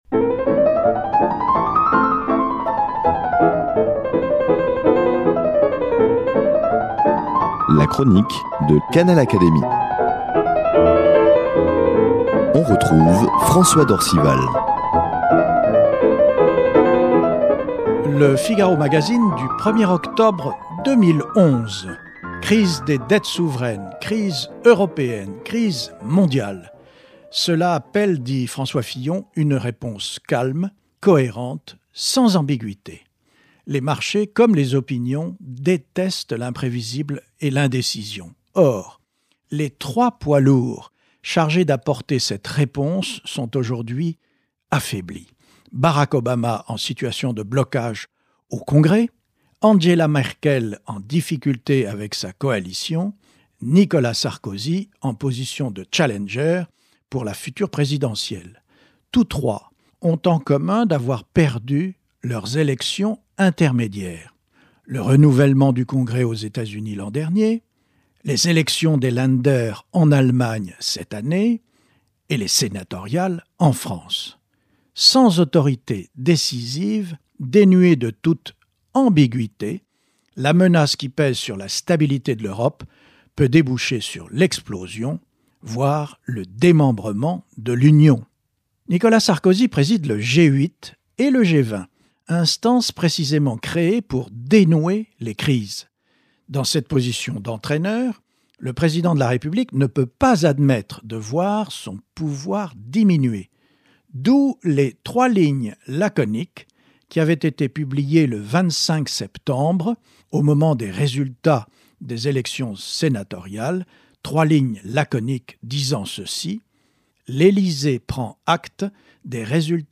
Plus que jamais d’actualité... la chronique de François d’Orcival
Elle est reprise ici par son auteur, avec l’aimable autorisation de l’hebdomadaire.